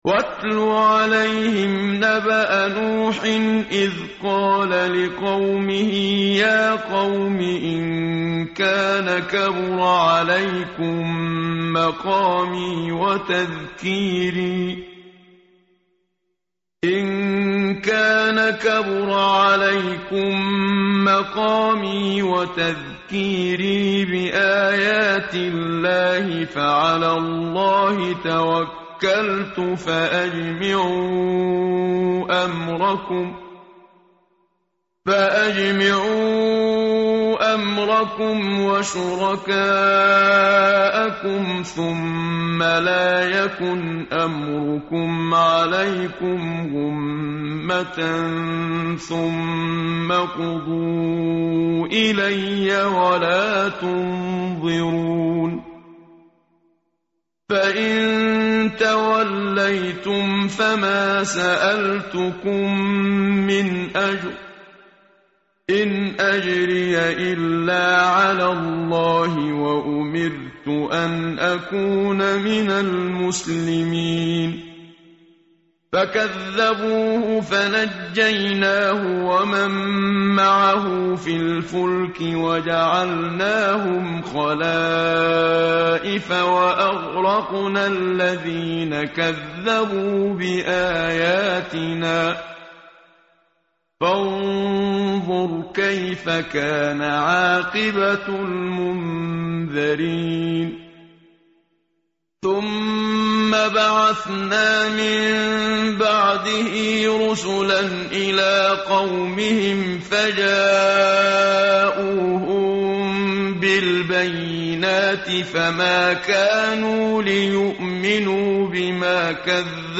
متن قرآن همراه باتلاوت قرآن و ترجمه
tartil_menshavi_page_217.mp3